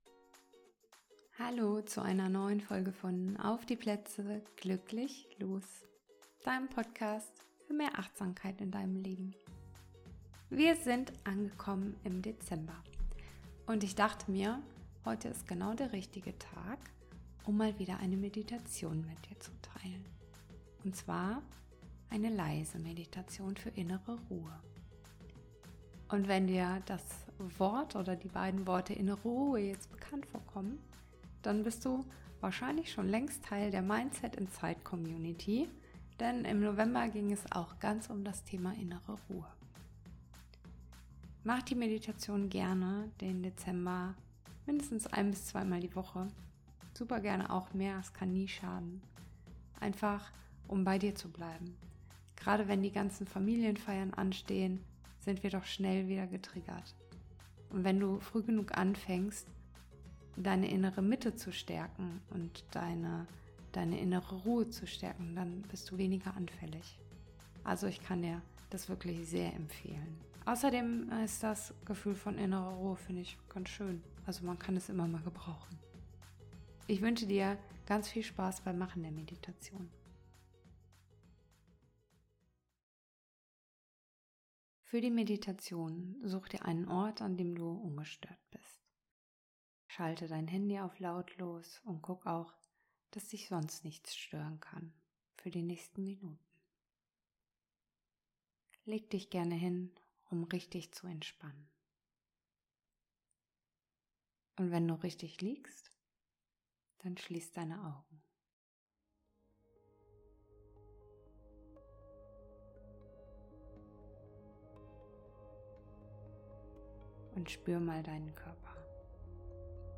1 Innere Ruhe finden: Deine Meditation für Gelassenheit im Dezember 10:18
1 Innere Ruhe finden: Deine Meditation für Gelassenheit im Dezember 10:18 Play Pause 21h ago 10:18 Play Pause Später Spielen Später Spielen Listen Gefällt mir Geliked 10:18 Heute freue ich mich, eine besondere Meditation mit dir zu teilen: eine stille, achtsame Reise zu deiner inneren Ruhe.
Meditation_innere_Ruhe.mp3